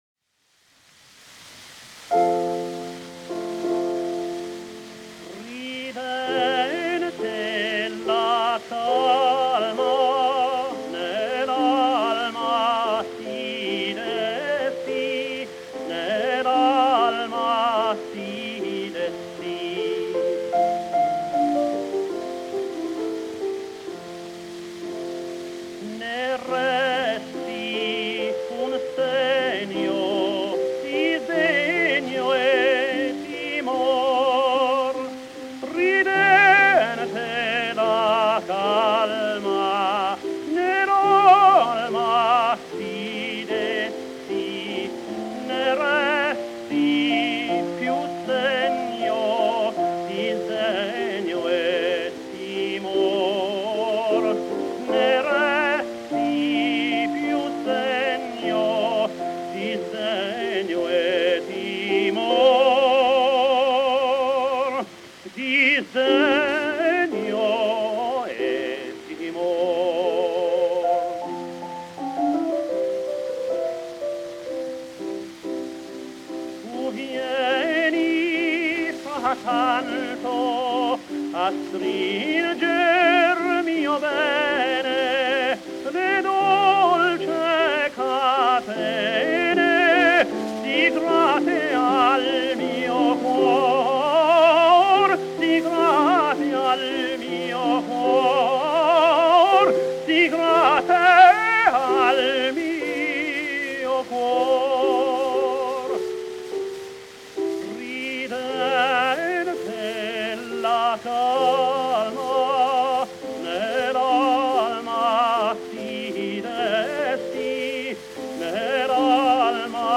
Tenore JOHN McCORMACK - (W.A.Mozart) "Ridente la calma"
Тенор ДЖОН МакКОРМАК - (В.А.Моцарт) "Ridente la calma"